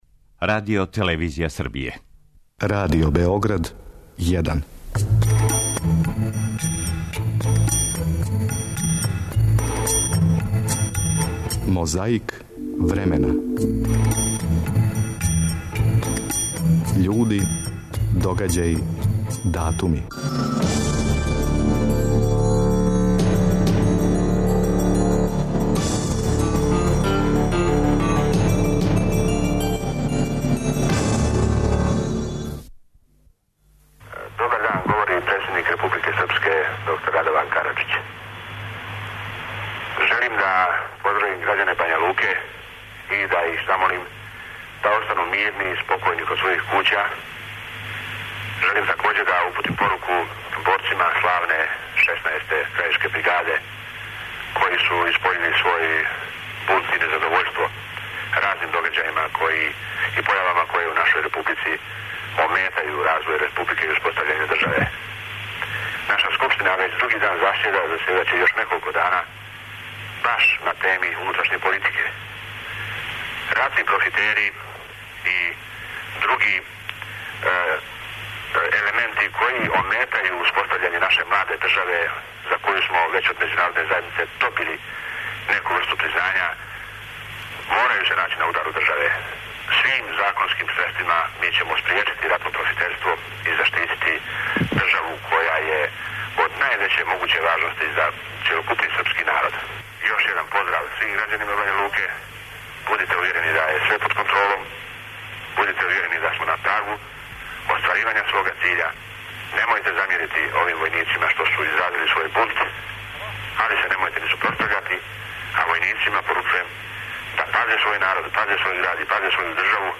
Чућемо и Радована Караџића који је 10. септембра 1993. смиривао побуну 16. крајишке бригаде у Бањалуци, која је блокирала центар града, и то говорећи против ратних профитера и залажући се за побољшање положаја бораца и инвалида.
Ни у овом случају не поседујемо тонски запис, али ћемо приредити једно мало књижевно преподне и прочитати по који пасус из његових романа.
Подсећа на прошлост (културну, историјску, политичку, спортску и сваку другу) уз помоћ материјала из Тонског архива, Документације и библиотеке Радио Београда.